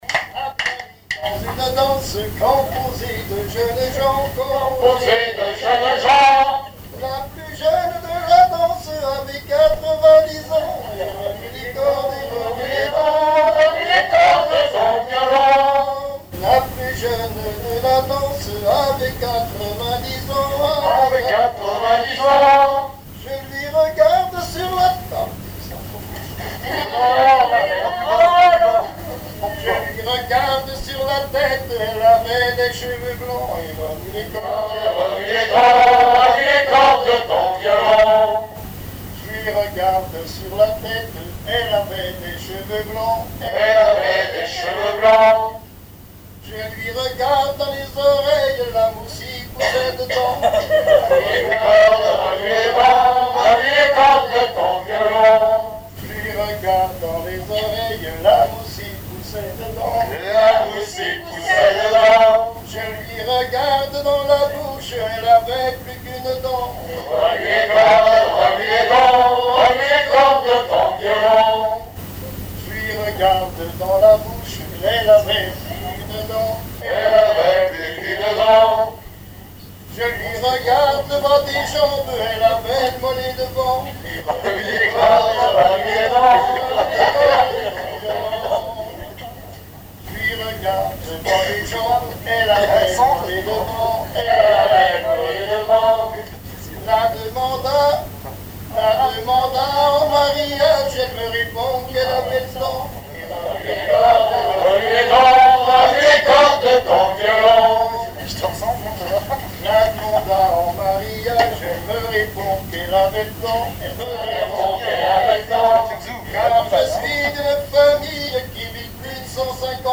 Genre laisse
Répertoire d'un bal folk par de jeunes musiciens locaux